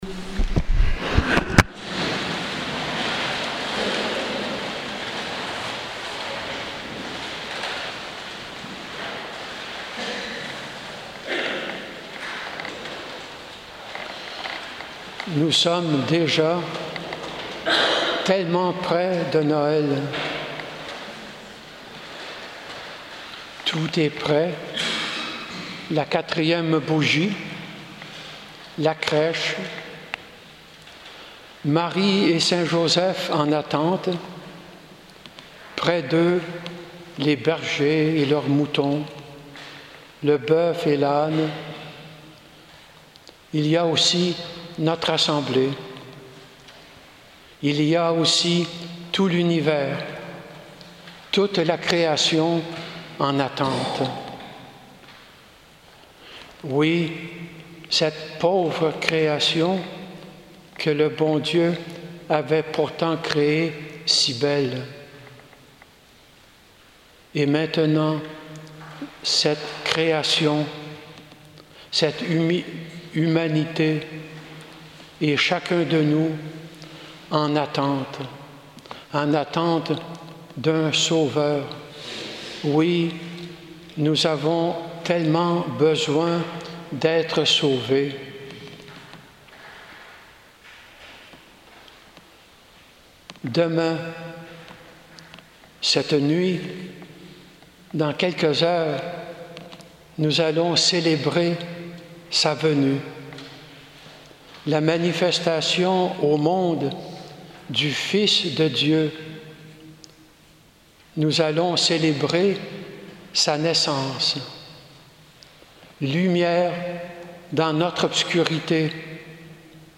Homélie du 24 décembre 2017 | Les Amis du Broussey